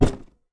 Punching Box Normal A.wav